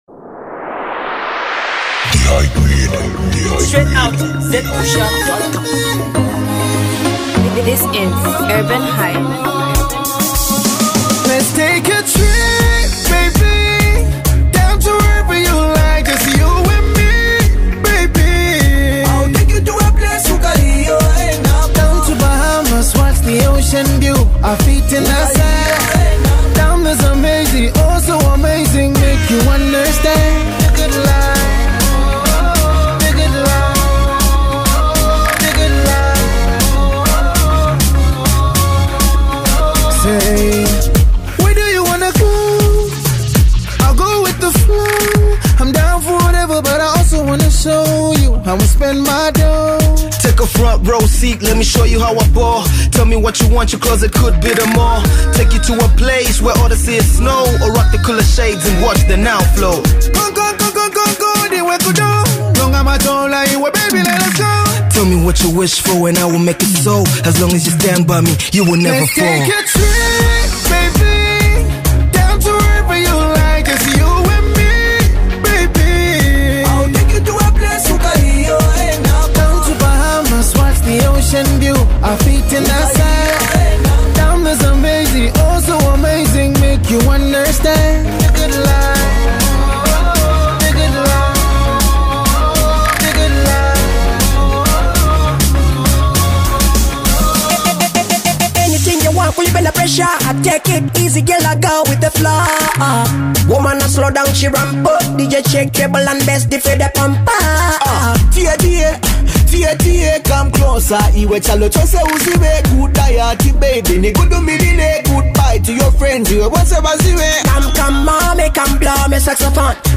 feel good music